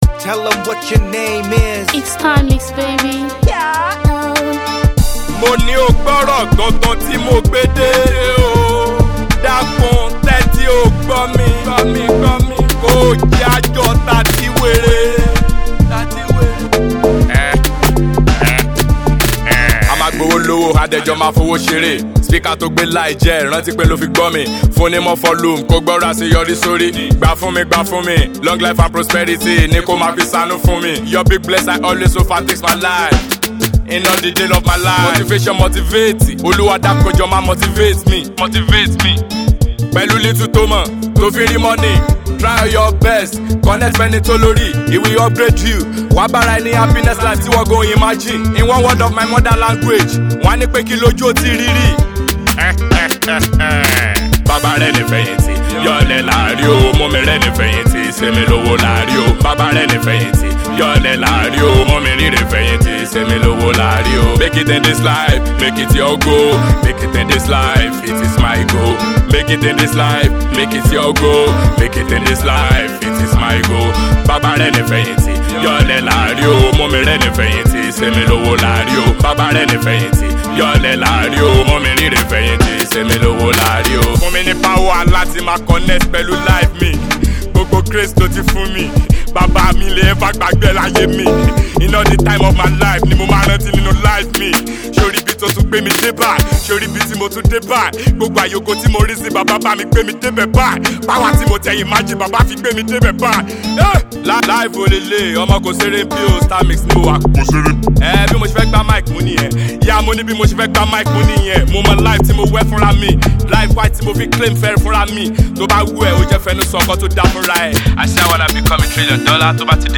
Nigerian singer/rapper